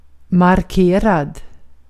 Ääntäminen
US US : IPA : [mɑɹkt]